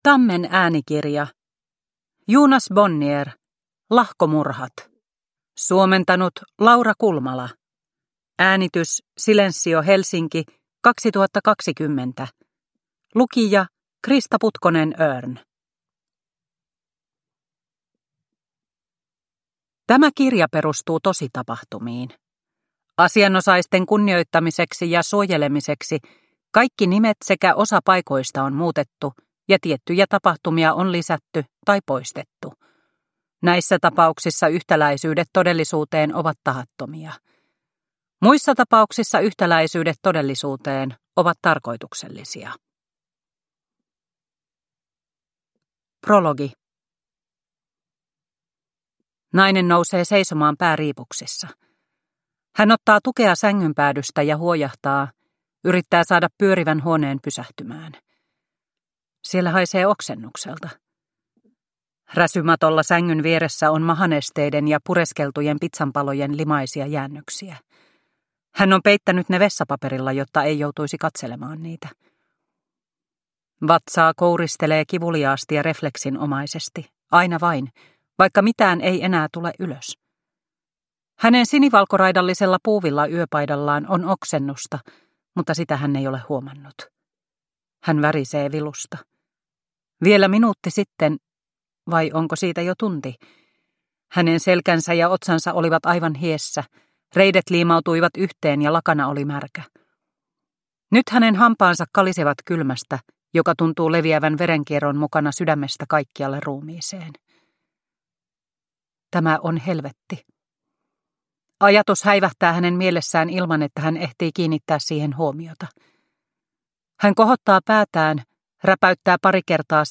Lahkomurhat – Ljudbok – Laddas ner